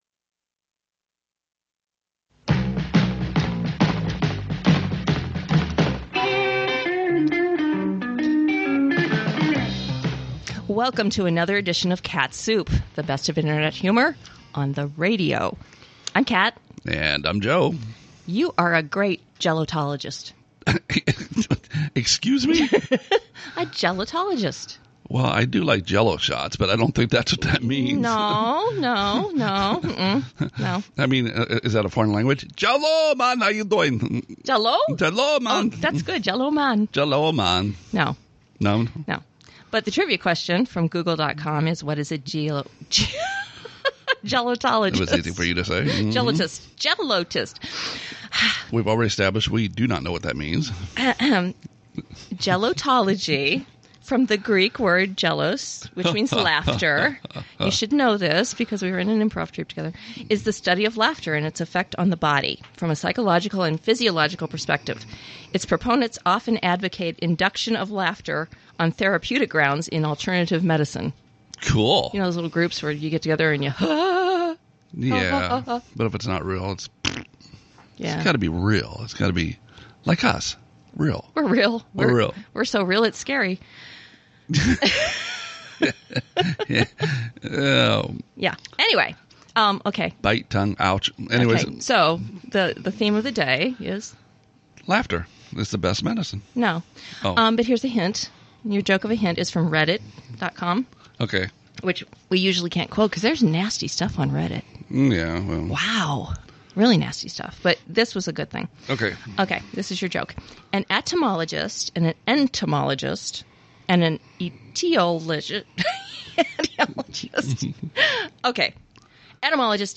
Melissopalynology (Me-Liss-o-pal-en-ol-ogy)